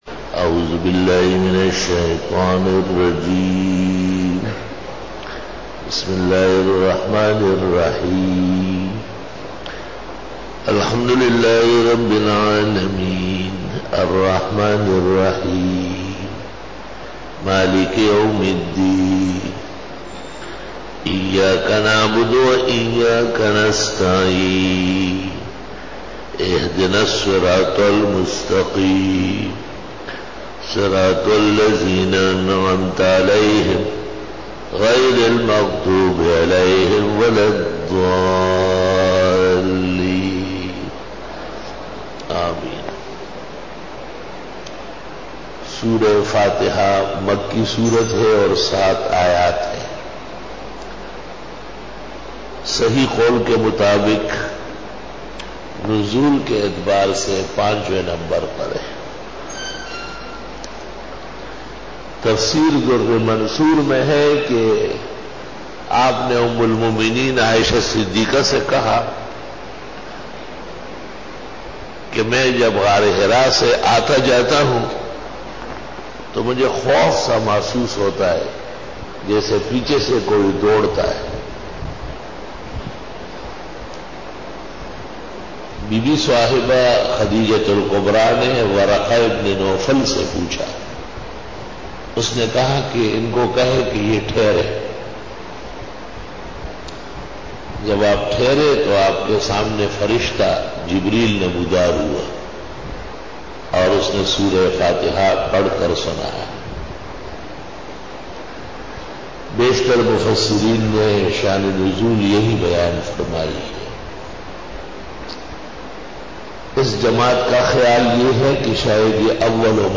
دورہ تفسیر